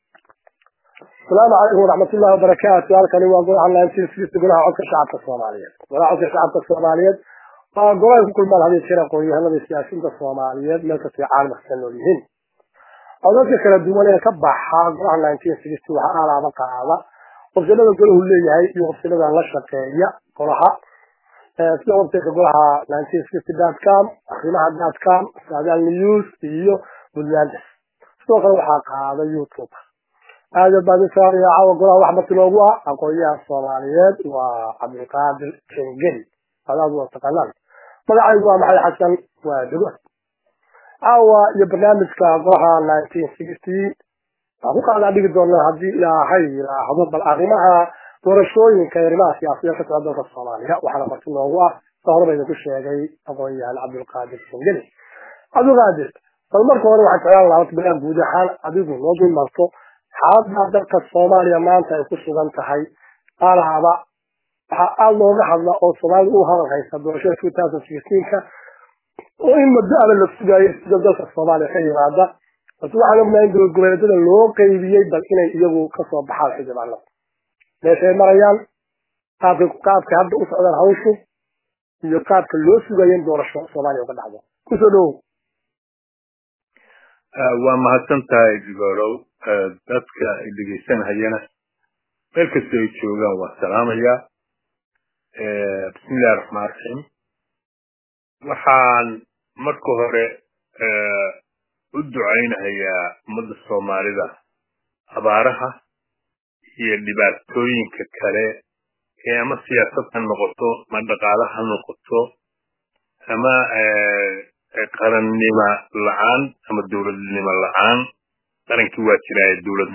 Dhagayso: waraysi aqoonyahan (SIYAASI)